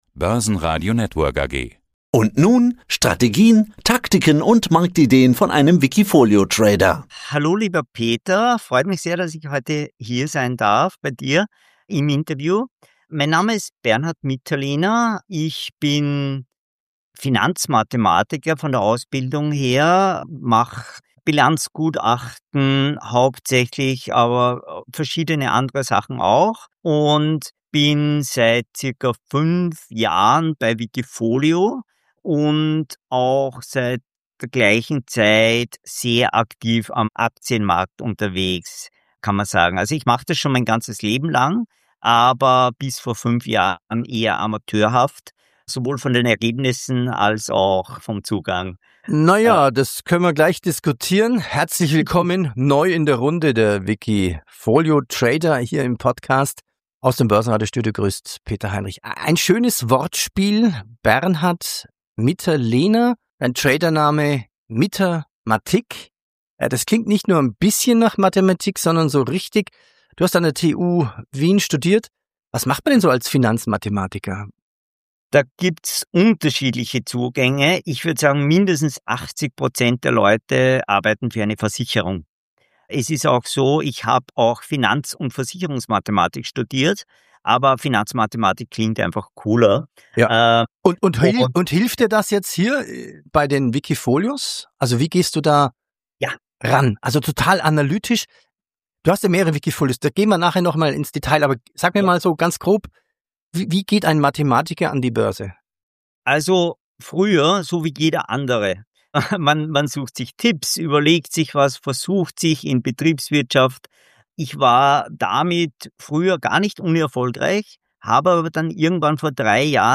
Dual Momentum praktisch: Rein in Stärke, raus bei Schwäche ~ Die besten wikifolio-Trader im Börsenradio Interview Podcast